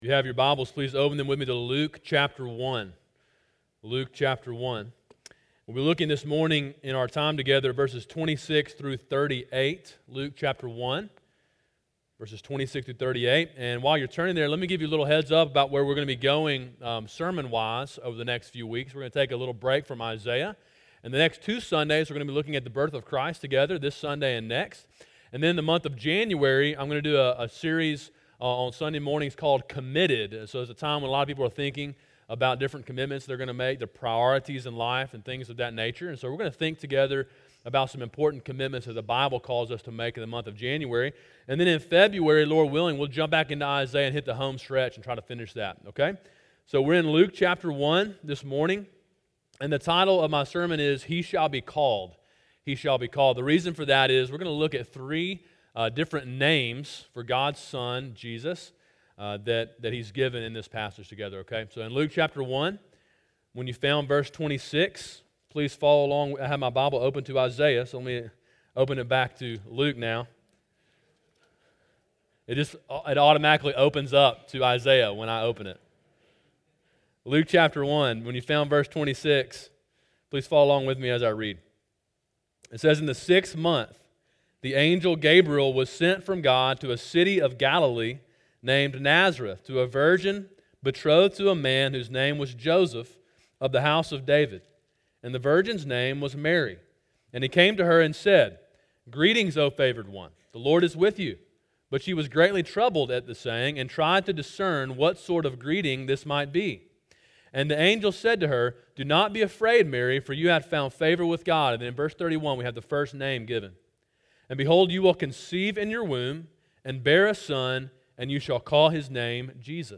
Sermon: “He Shall Be Called” (Luke 1:26-38) – Calvary Baptist Church